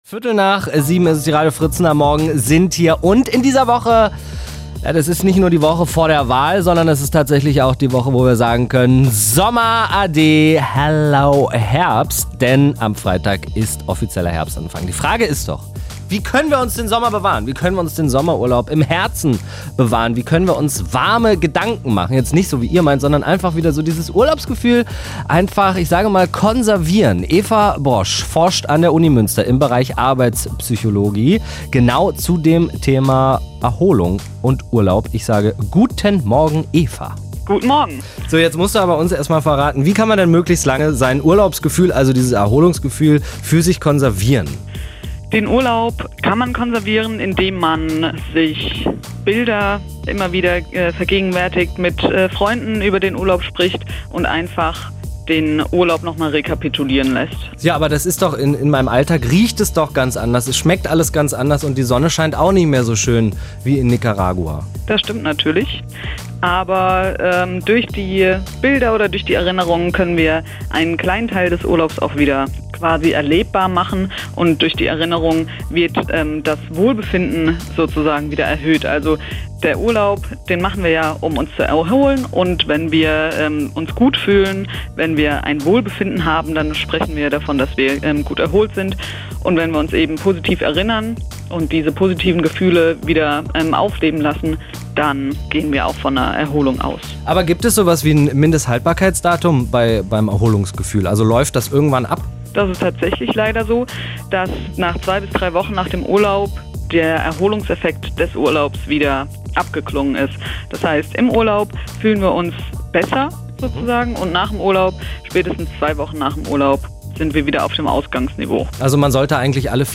Radio Fritz-Interview